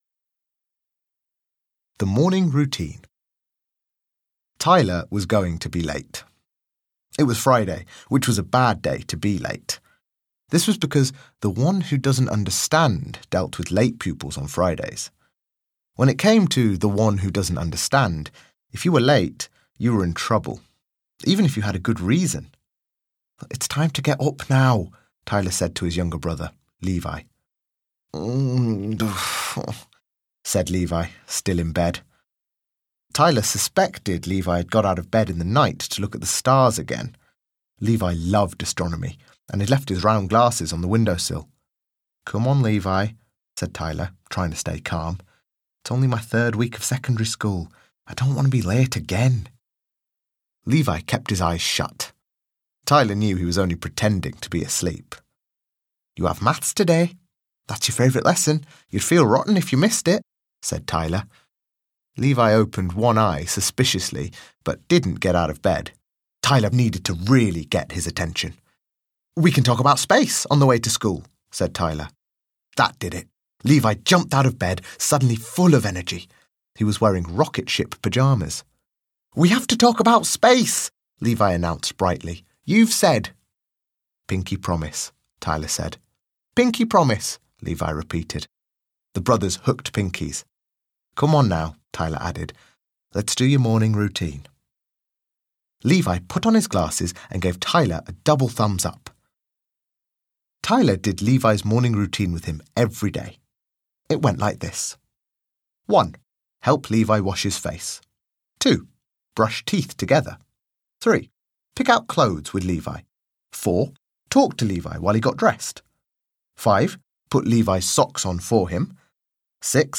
Audio knihaThe Late Crew (EN)
Ukázka z knihy